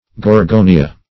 Gorgonia \Gor*go"ni*a\ (g[^o]r*g[=o]"n[i^]*[.a]), n. [L., a